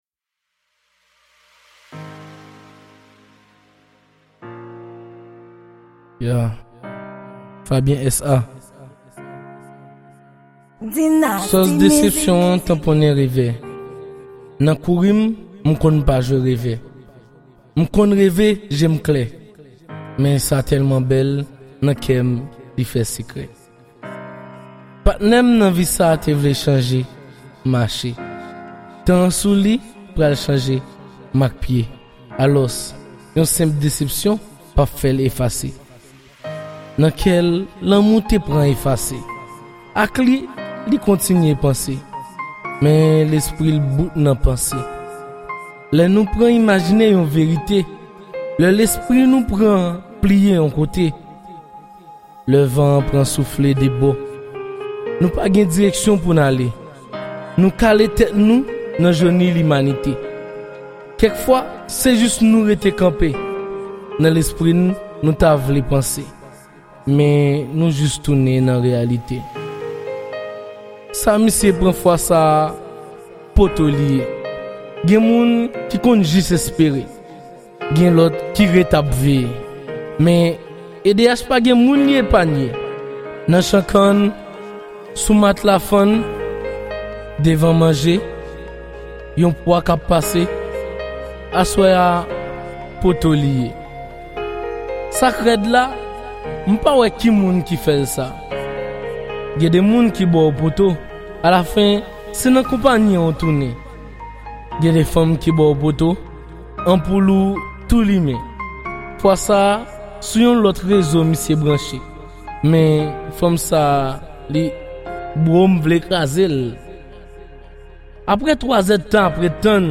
Genre: Slam